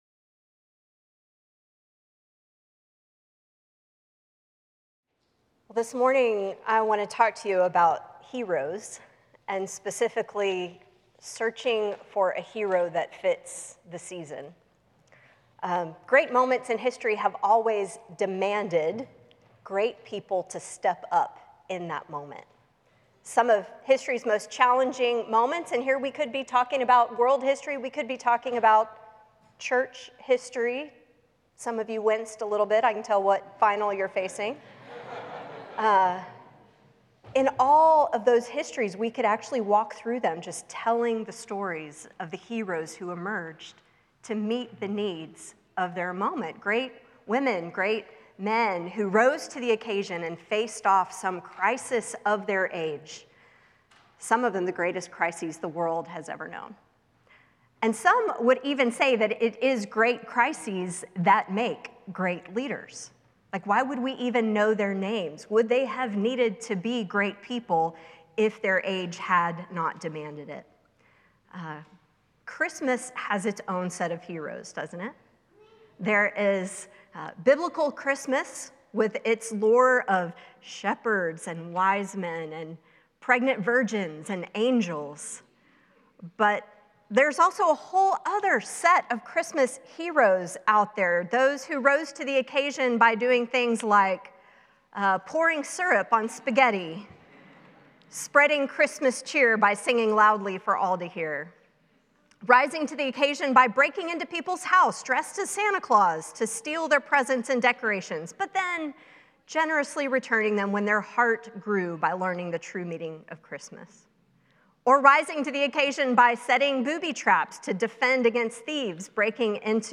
The following service took place on Wednesday, December 4, 2024.